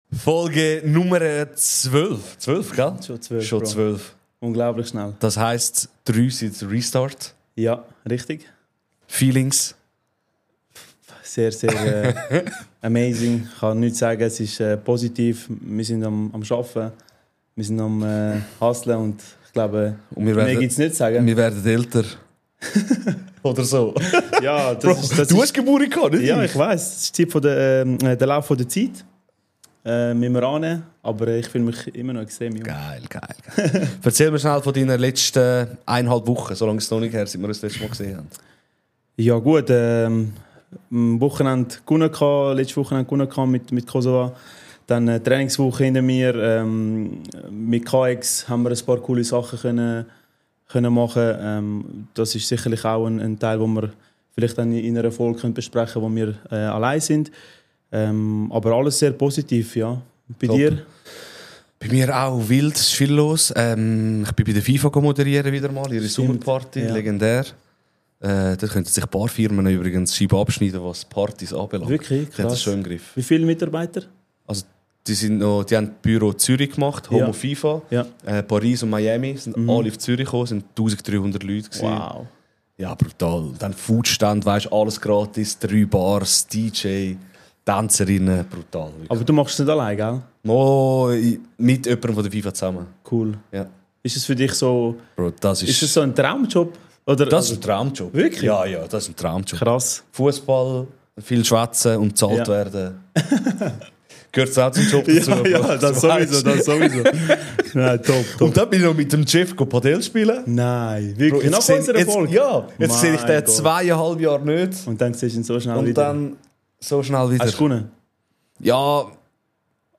Ehrlich, tiefgründig und mit einer guten Portion Humor – eine Folge über Identität, Stolz und das Gefühl, dazuzugehören.